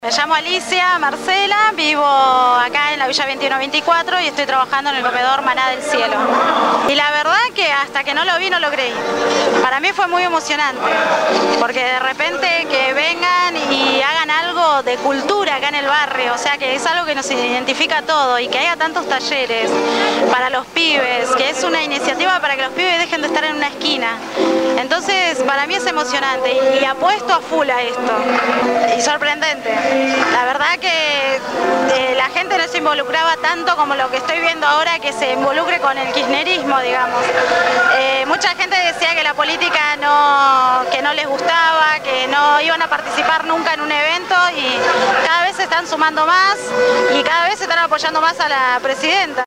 El sábado 4 de junio se llevó adelante la jornada solidaria «Somos Ambiente» en la Villa 21-24.